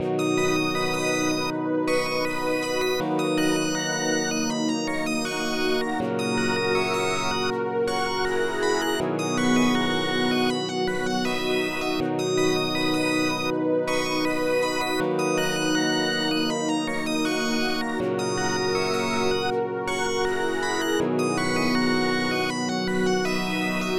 Ich habe 2 Presets genommen und diese jeweils mit verschiedenen Instrumenten ausgestattet. Außerdem habe ich das Tempo verändert.